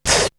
Beatbox 9.wav